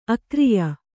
♪ akriya